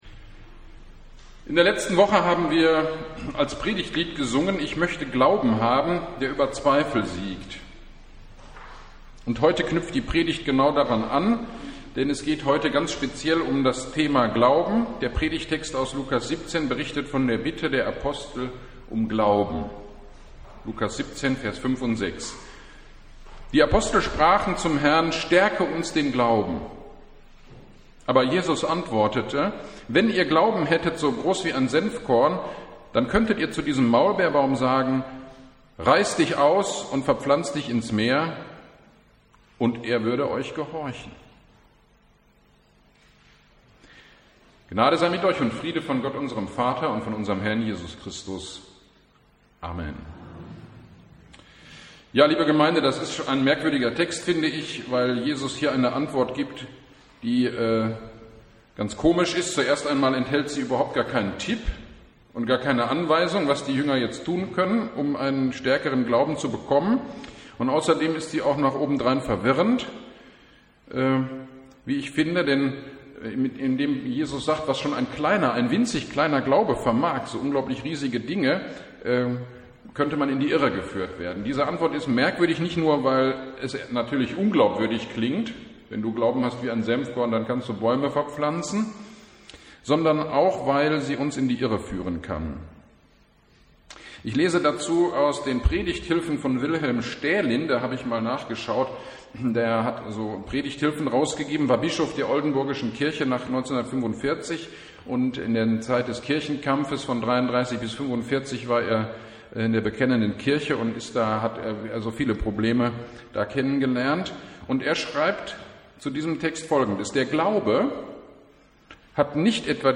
Predigt über Lukas 17,5-6: Stärke uns den Glauben - Kirchgemeinde Pölzig